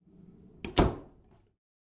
描述：浴室的门慢慢关闭，失去了混响。
Tag: 点击 柔软 贴身 踩住